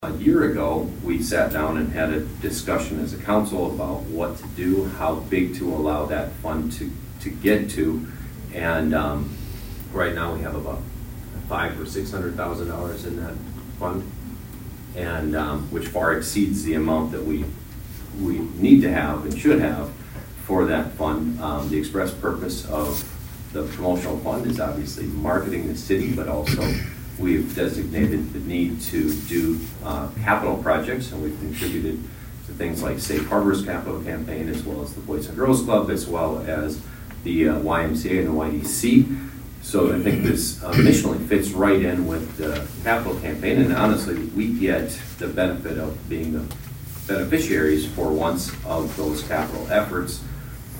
Schaunaman told the council the money would come from the City’s Promotion Fund.